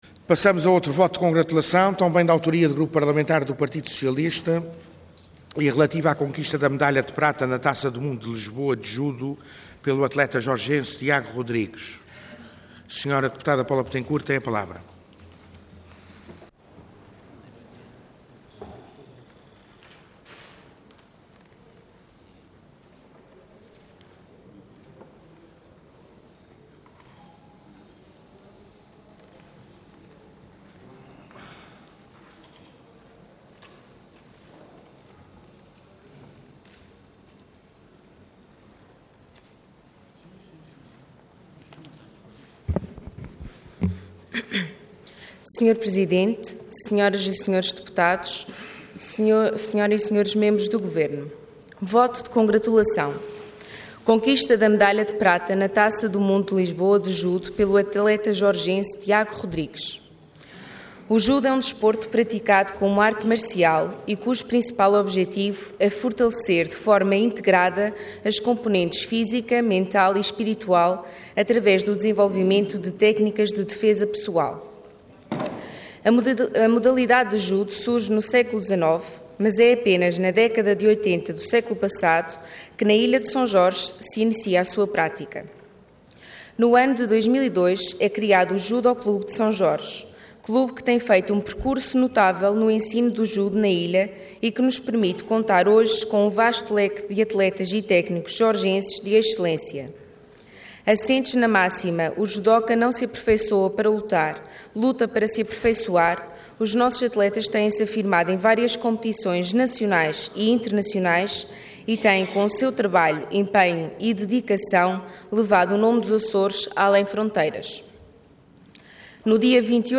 Intervenção Voto de Congratulação Orador Paula Bettencourt Cargo Deputada Entidade PS